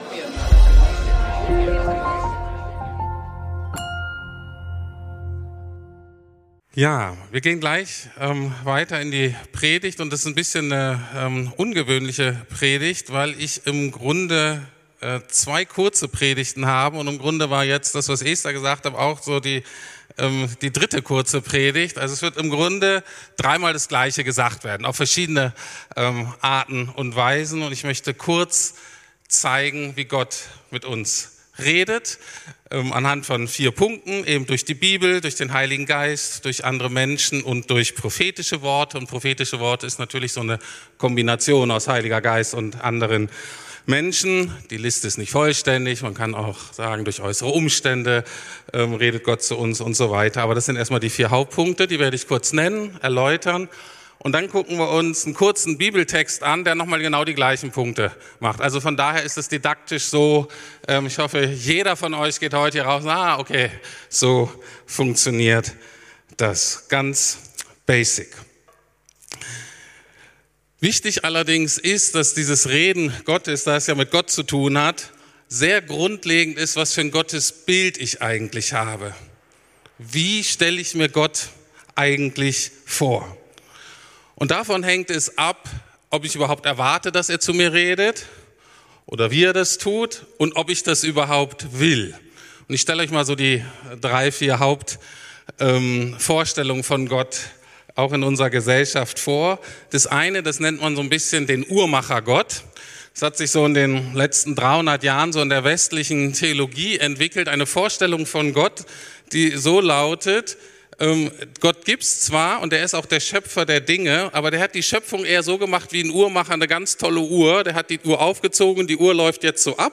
Gott redet ~ Predigten der LUKAS GEMEINDE Podcast